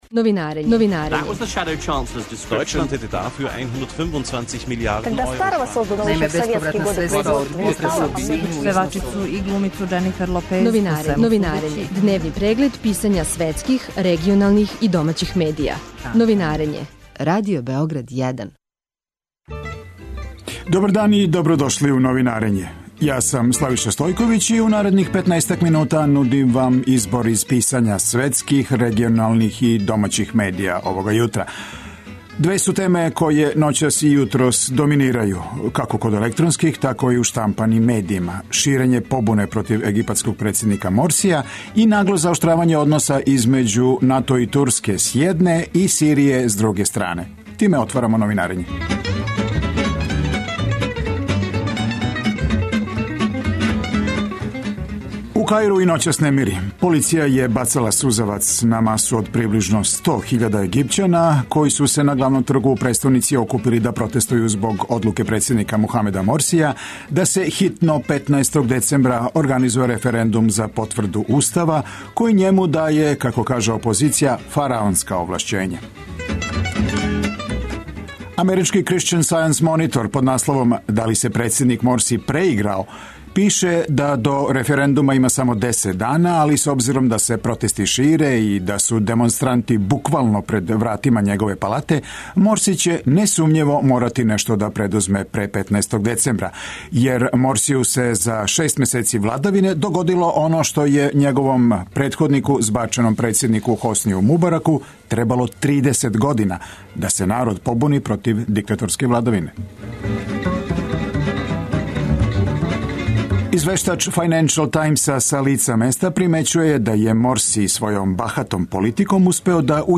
Повод за разговор је формирање Владе Црне Горе.